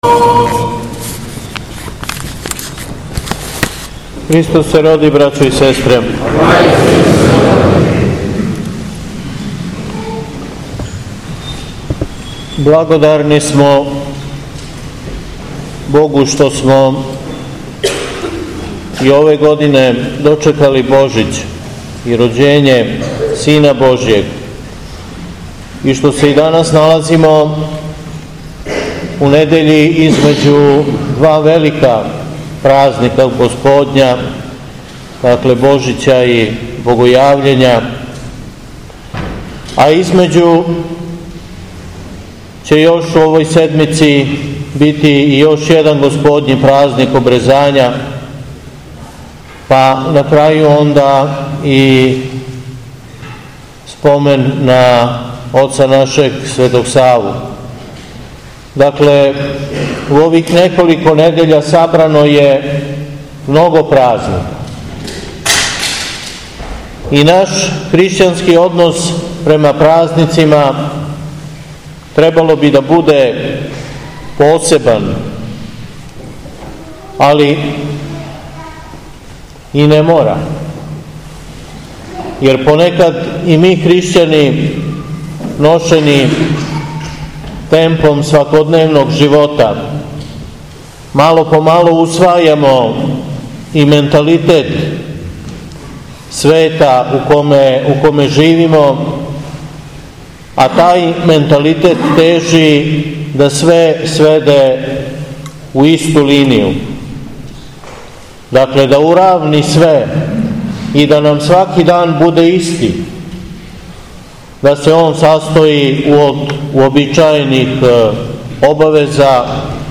СВЕТА АРХИЈЕРЕЈСКА ЛИТУРГИЈА И САСТАНАК АРХИЈЕРЕЈСКИХ НАМЕСНИКА ЕПАРХИЈЕ ШУМАДИЈСКЕ У АРАНЂЕЛОВЦУ - Епархија Шумадијска
Беседа